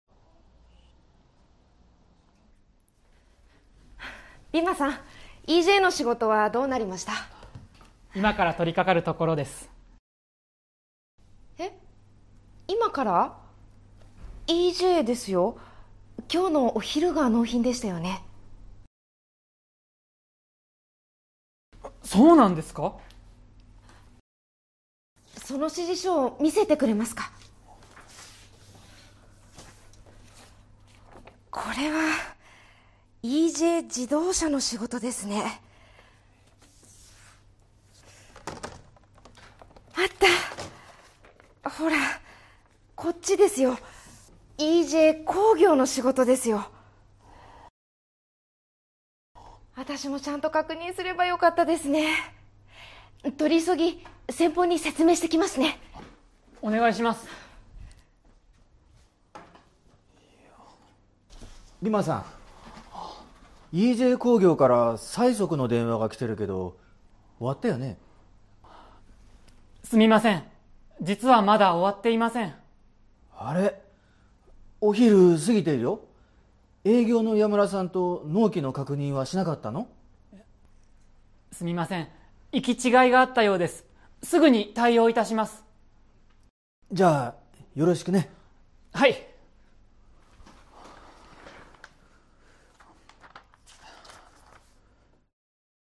Role-play Setup
The intonation is rising in this case.